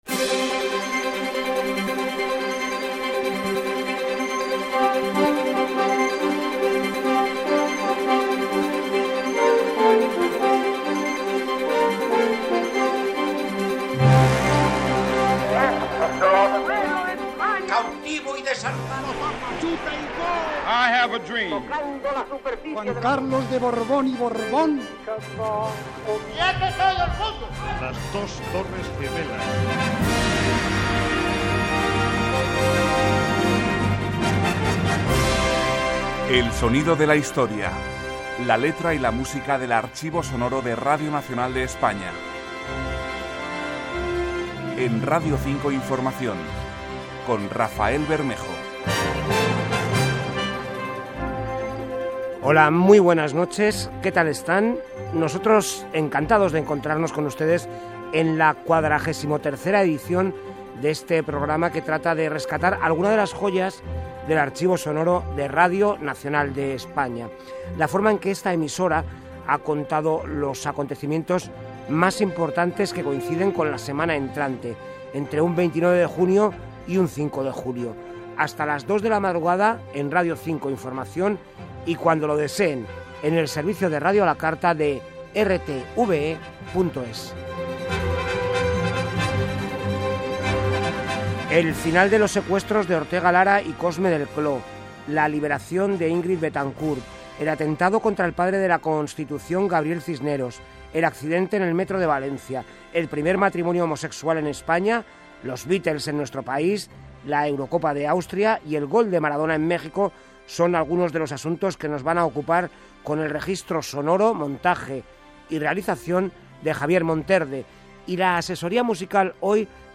Careta del programa, presentació amb el sumari de continguts, record a la mort de Camarón de la Isla.
Divulgació